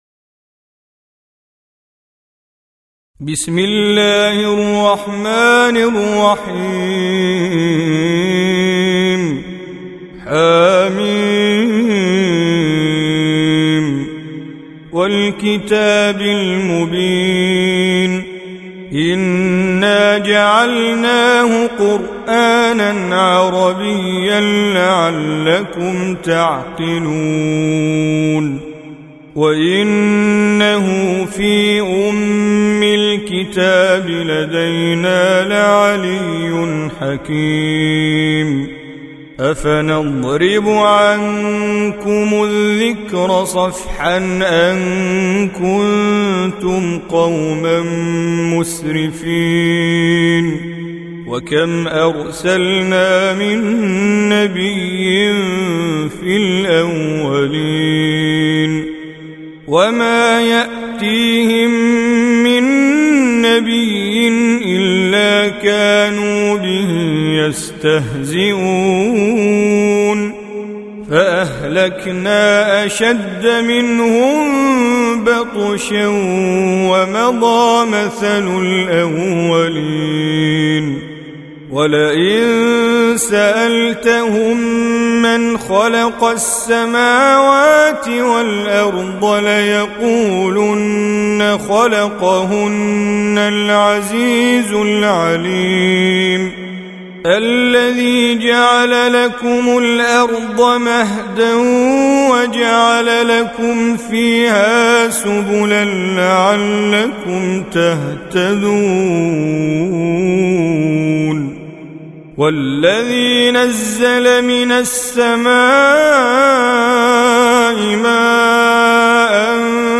Surah Az-Zukhruf سورة الزخرف Audio Quran Tajweed Recitation
حفص عن عاصم Hafs for Assem